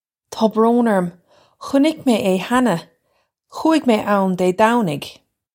Taw brone urrum, hunik may ay hanna. Khoo-ig may own Day Dow-nig.
This is an approximate phonetic pronunciation of the phrase.
This comes straight from our Bitesize Irish online course of Bitesize lessons.